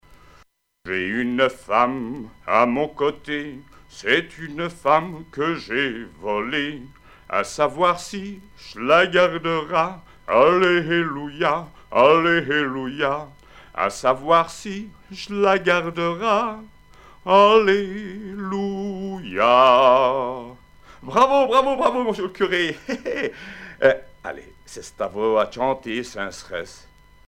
circonstance : fiançaille, noce ;
Pièce musicale éditée